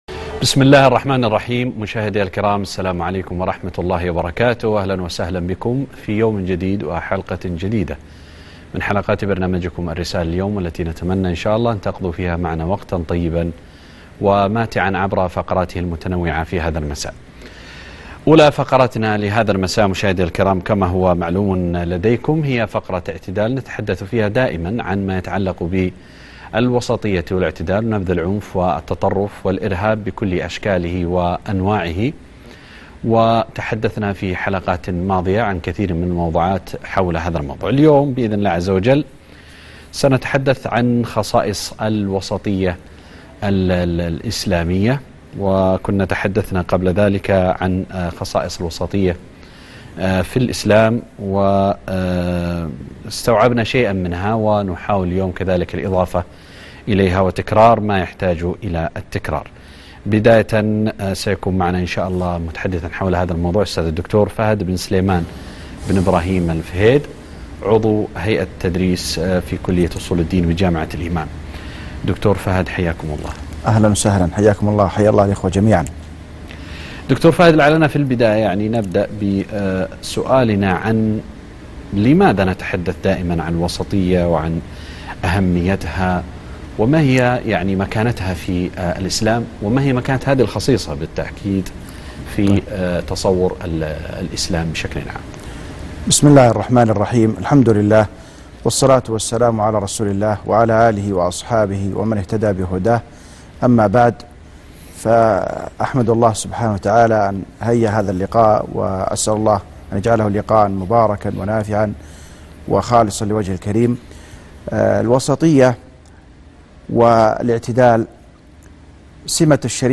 لقاء تلفزيوني بعنوان خصائص الوسطية الاسلامية - قناة الرسالة